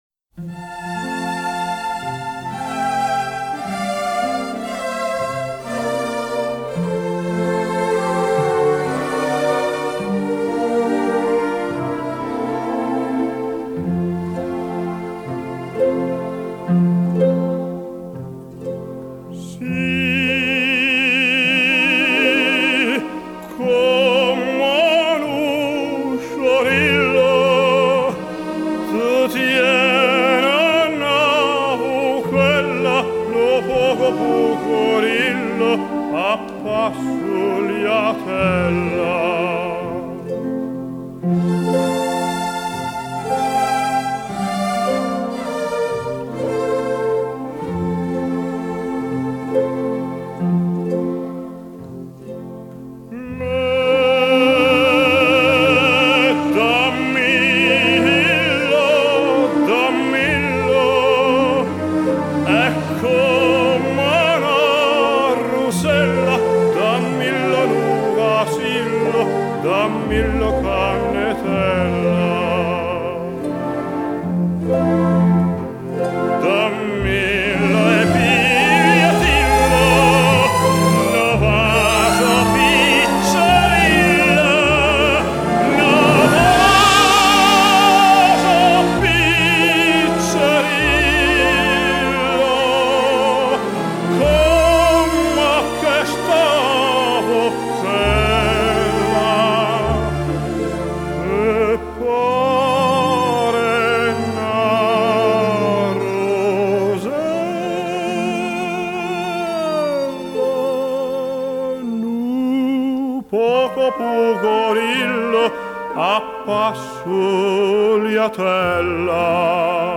【所属类别】音乐 世界音乐